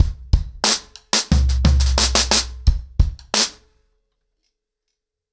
ROOTS-90BPM.41.wav